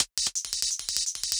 UHH_ElectroHatA_170-04.wav